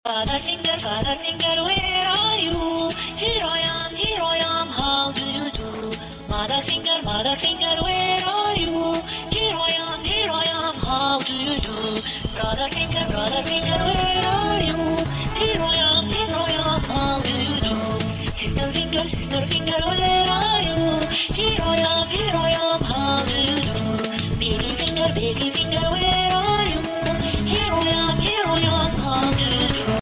nursery rhymes kids song